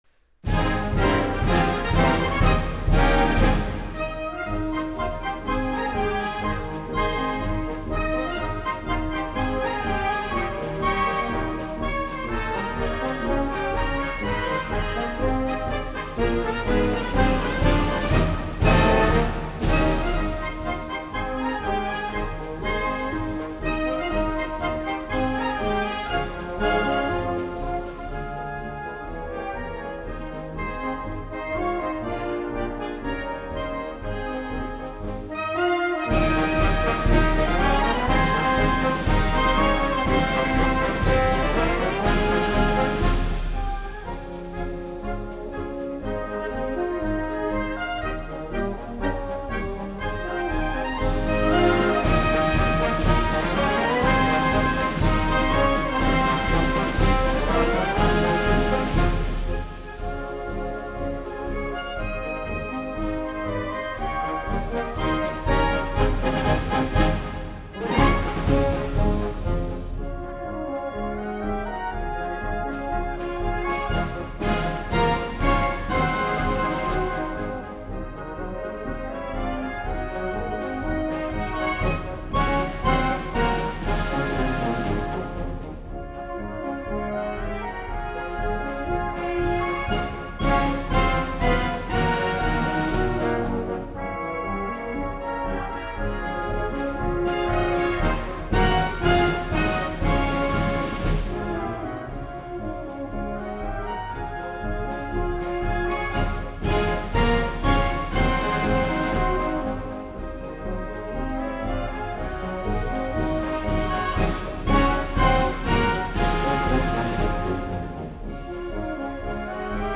这是一张不吵的进行曲录音，它虽然没有办法换下您家中的1812炮声，